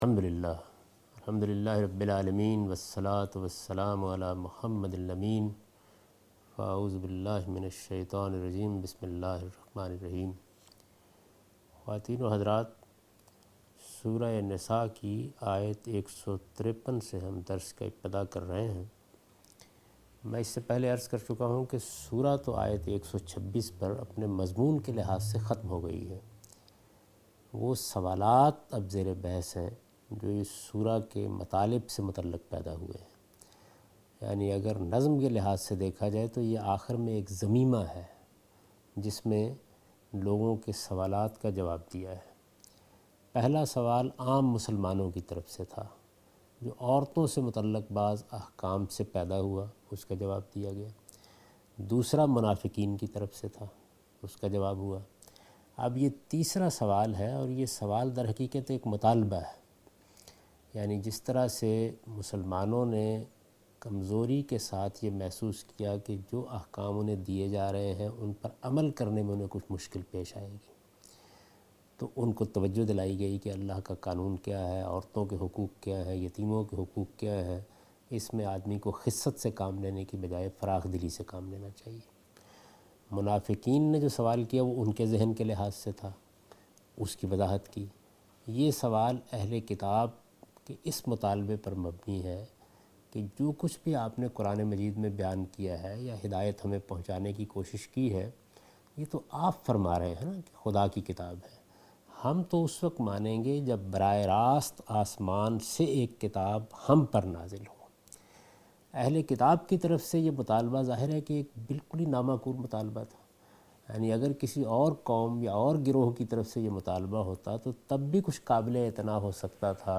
Surah Al-Nisa - A Lecture of Tafseer ul Quran Al-Bayan by Javed Ahmed Ghamidi